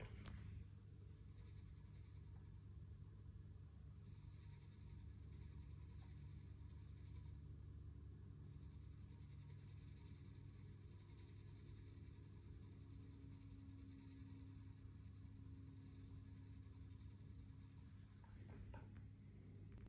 Lüfter macht komische Geräusche
Einer meiner Frontpanel Lüfter macht beim Drehen komische Geräusche.